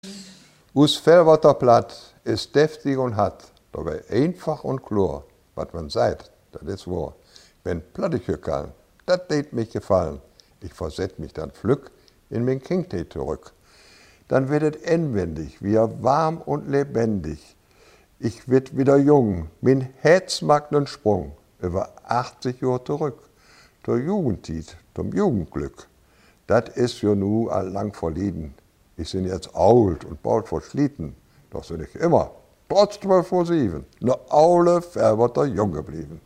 Hörprobe zum Tag der Muttersprache